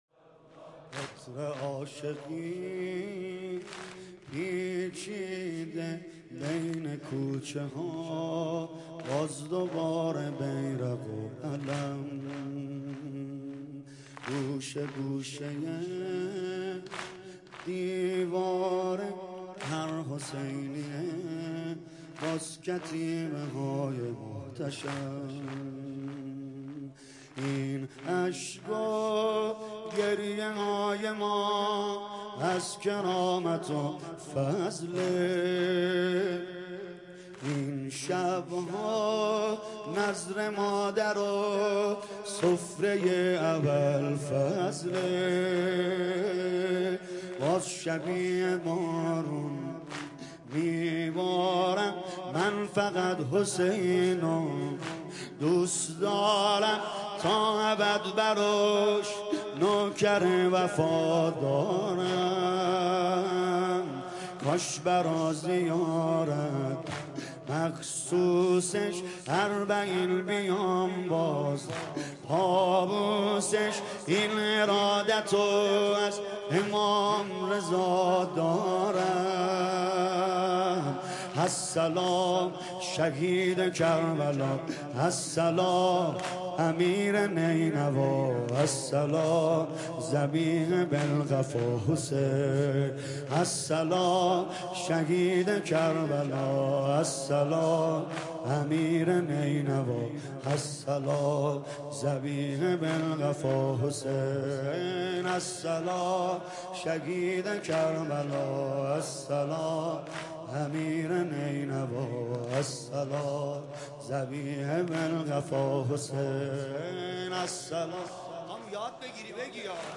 نوحه جديد
مداحی محرم